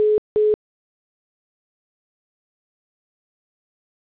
callwaiting_es.wav